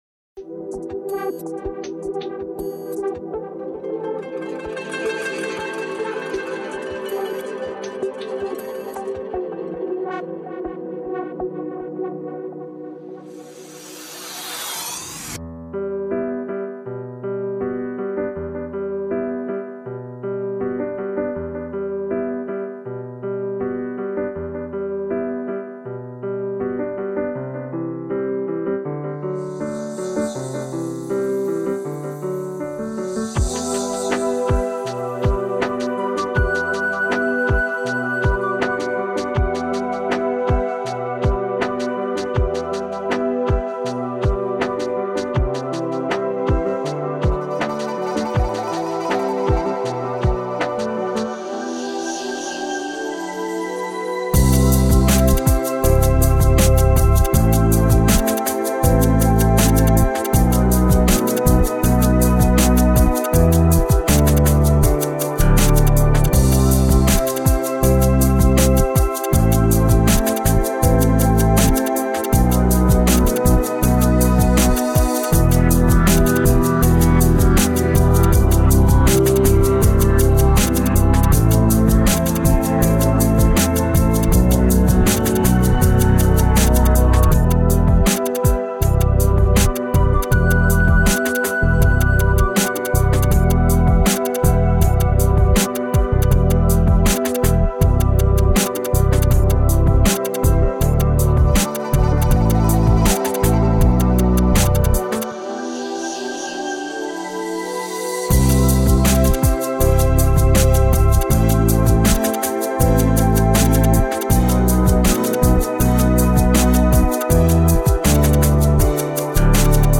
Категория: Скачать Русские минуса